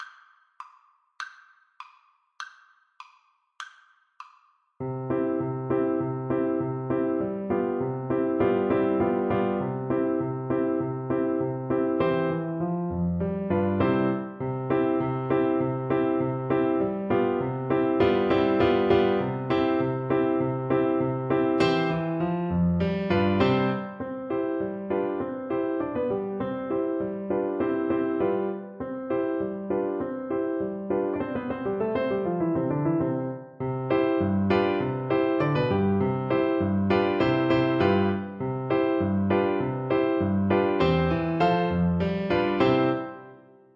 2/4 (View more 2/4 Music)
Allegretto
C4-G5